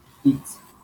IPA/it/